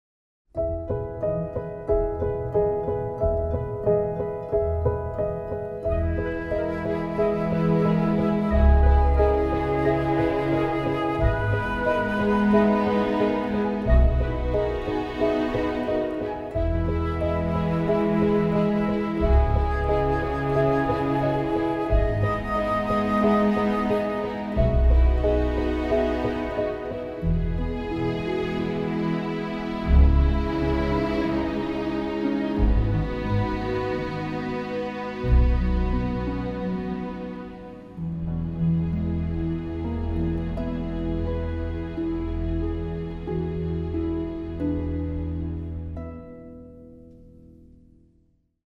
Celtic-flavoured orchestral score